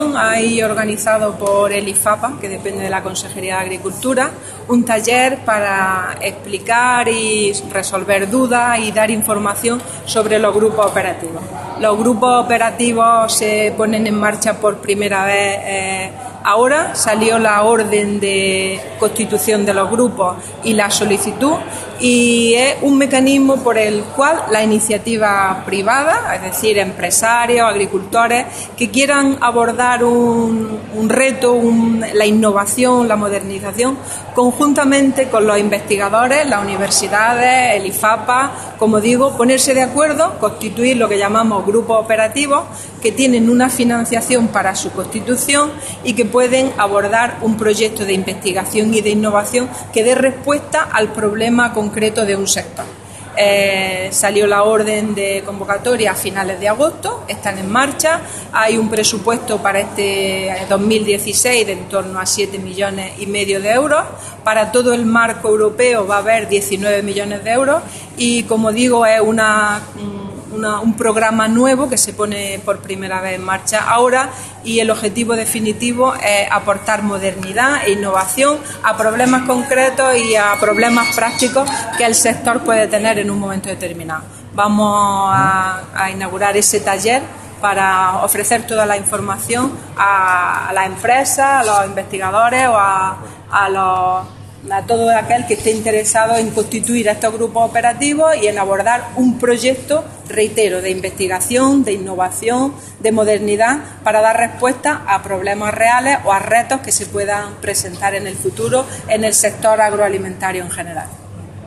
Declaraciones de Carmen Ortiz sobre ayudas a Grupos Operativos de Innovación